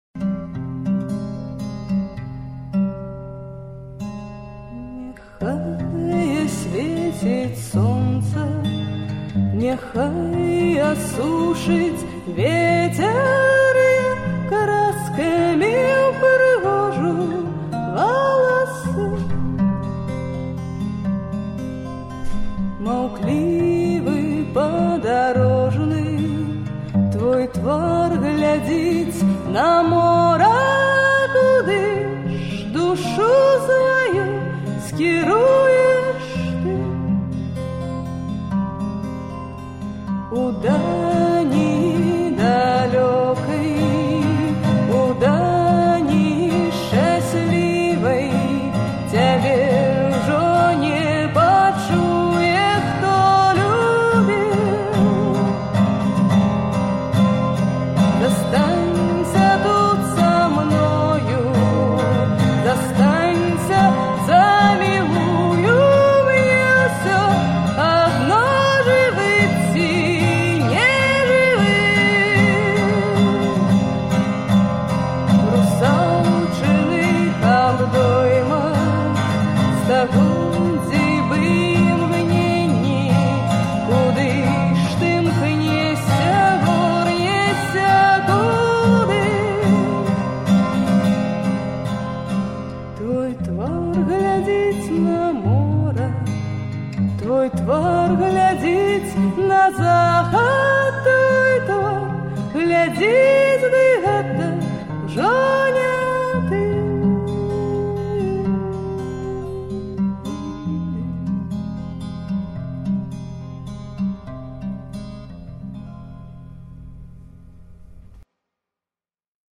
архіўны запіс